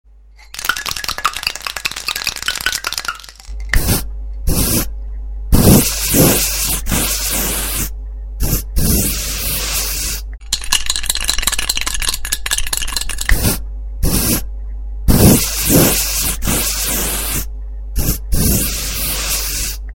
spray-paint-sound-effect_25357.mp3